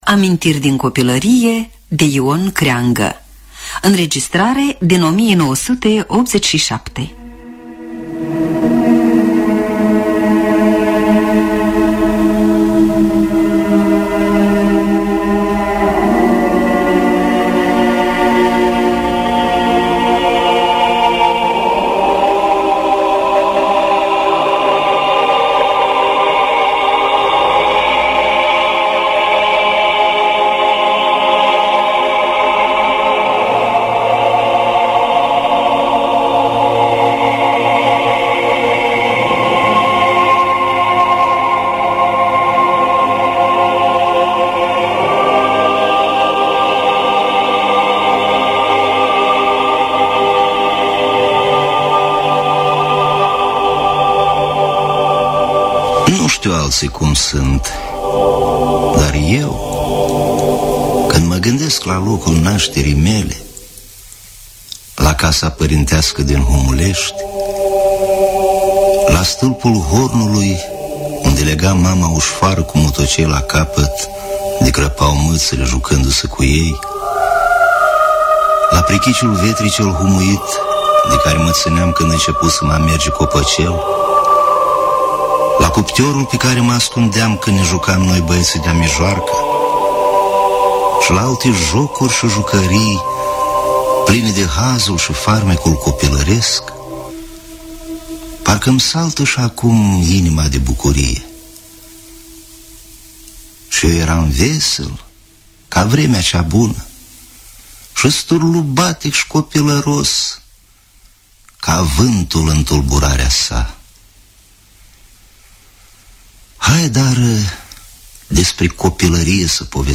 “Amintiri din copilărie” de Ion Creangă. Adaptarea radiofonică